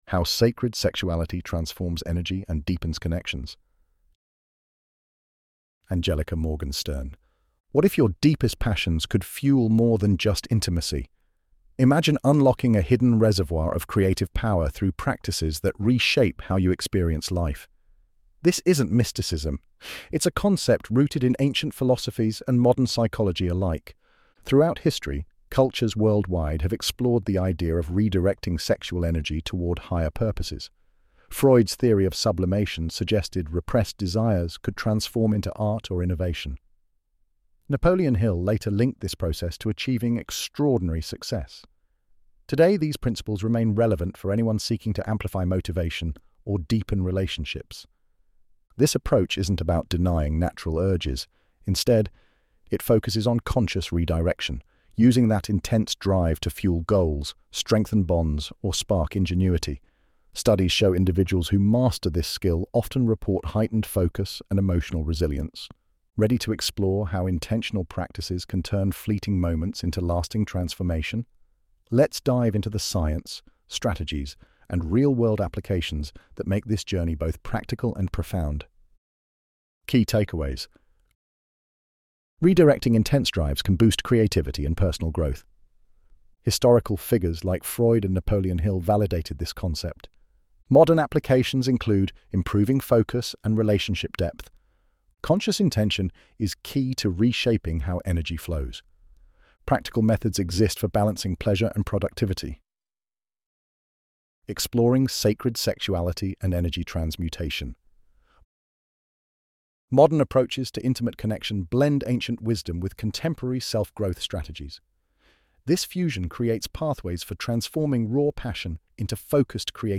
ElevenLabs_How_Sacred_Sexuality_Transforms_Energy_and_Deepens_Connections.mp3